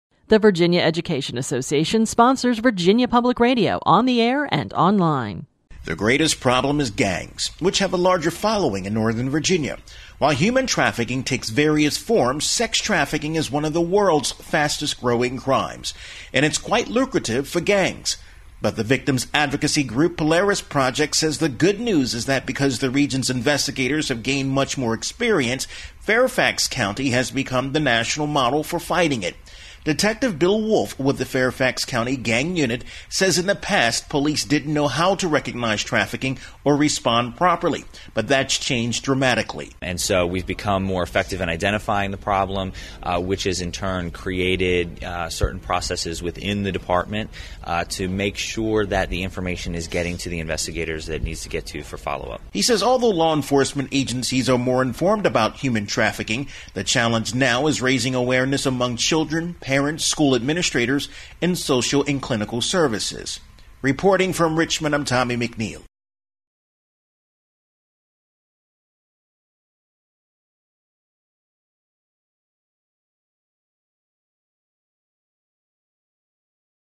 This entry was posted on October 6, 2013, 1:21 pm and is filed under Daily Capitol News Updates.